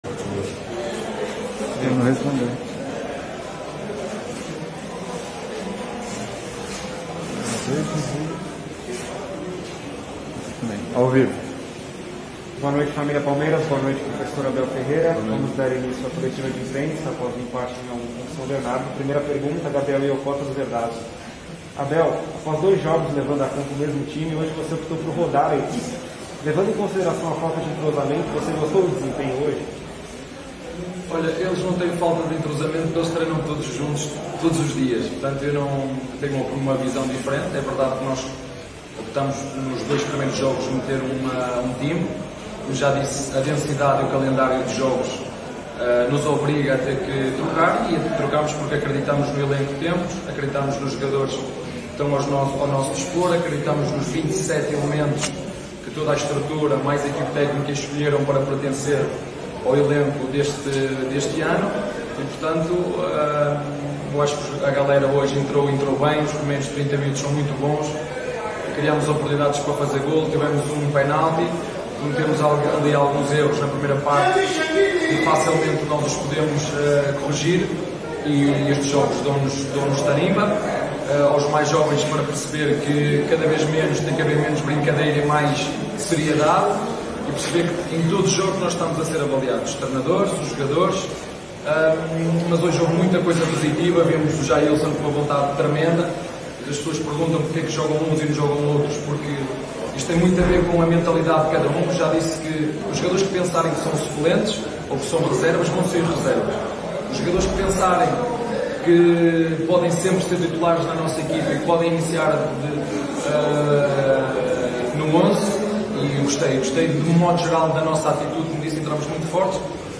COLETIVA-ABEL-FERREIRA-_-SAO-BERNARDO-X-PALMEIRAS-_-PAULISTA-2022.mp3